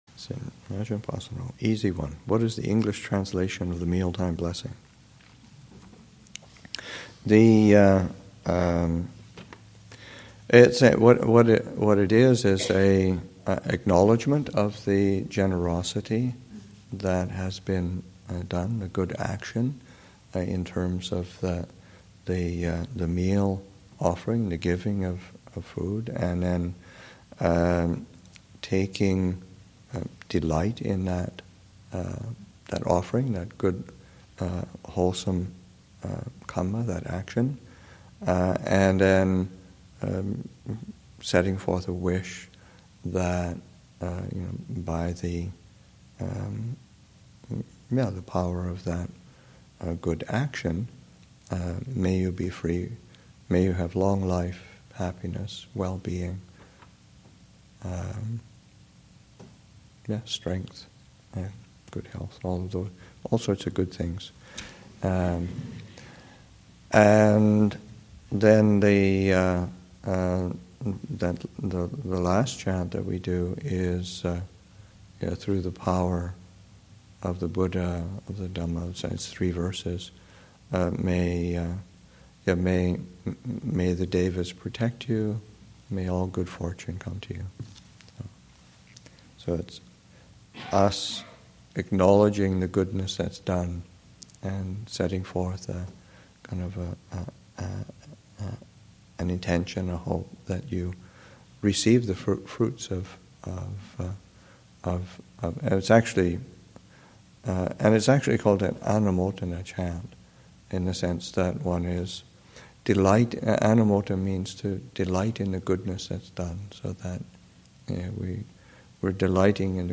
2014 Thanksgiving Monastic Retreat, Session 4 – Nov. 25, 2014